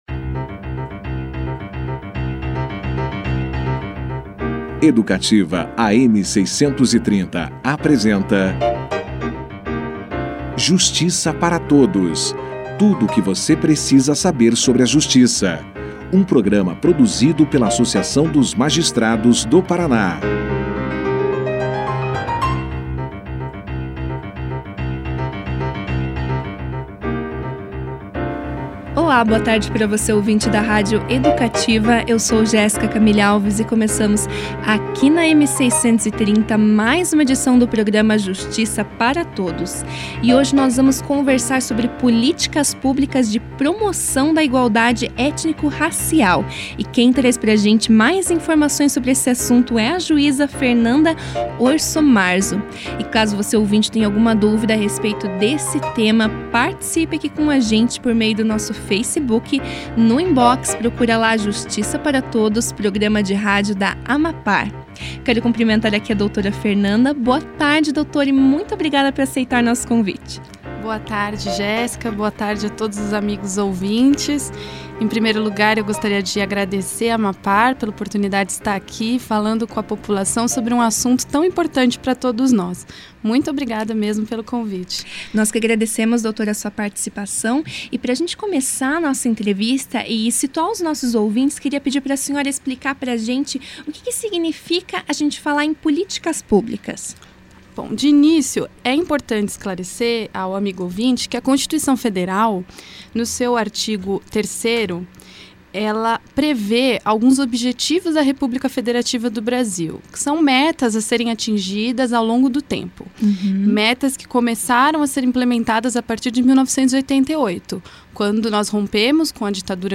Nesta quarta-feira (8), a juíza Fernanda Orsomarzo compareceu aos estúdios da rádio Educativa, AM 630, para explicar ao ouvinte do programa Justiça para Todos sobre políticas públicas de promoção de igualdade étnico-racial.
Confira a entrevista na íntegra com a juíza Fernanda Orsomarzo